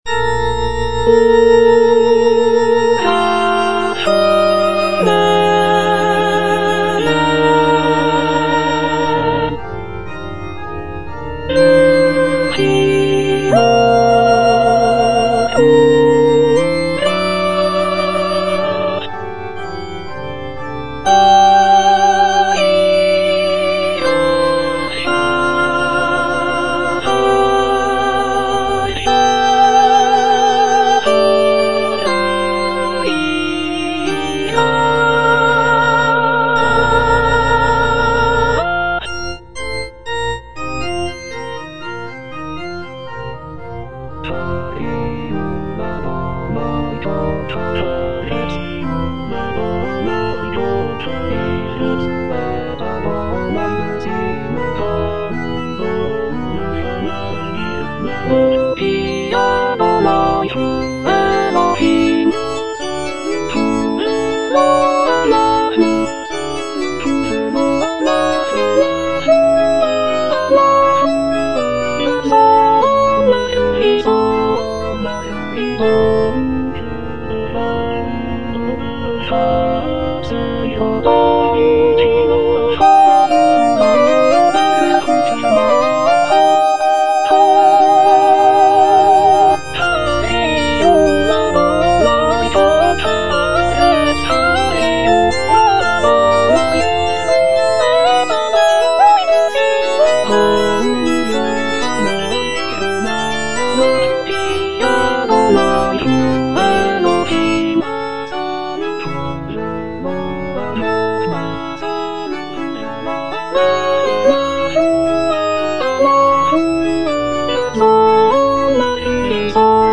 soprano II) (Emphasised voice and other voices) Ads stop